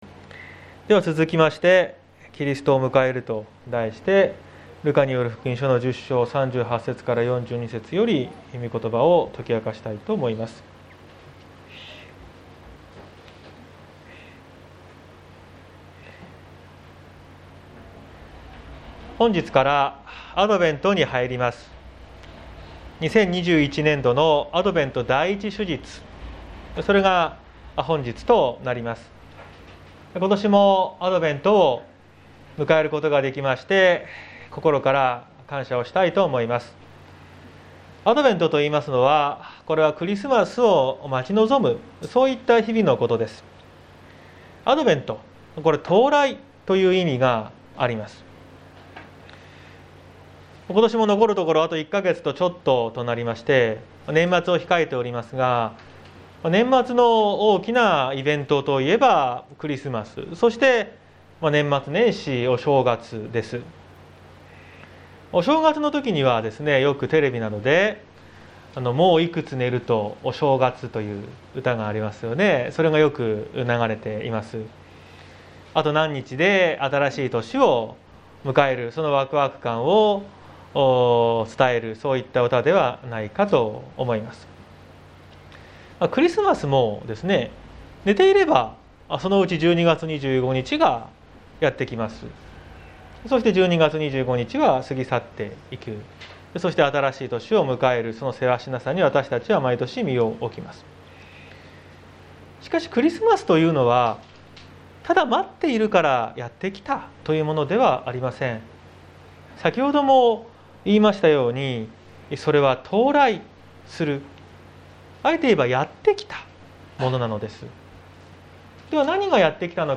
2021年11月28日朝の礼拝「キリストを迎える」綱島教会
説教アーカイブ。